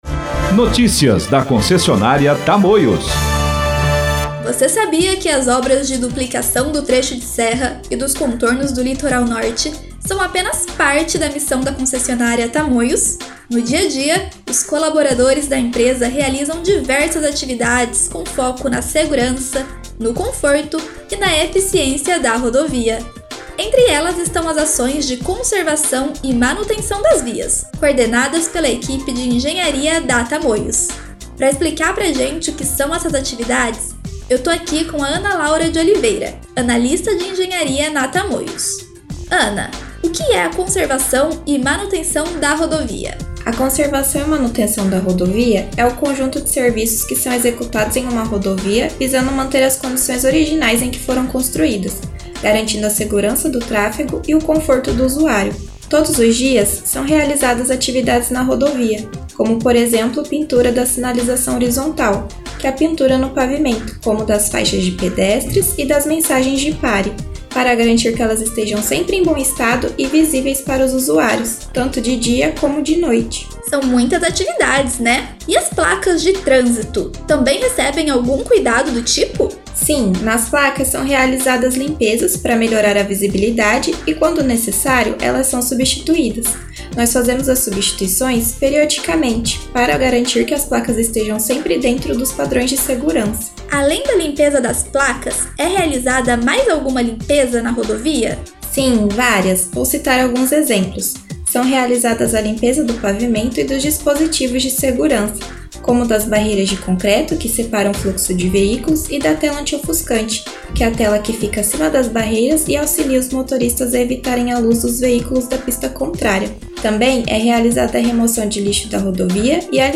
Clique na imagem para ouvir matéria produzida para Rádio Web Tamoios em julho de 2023.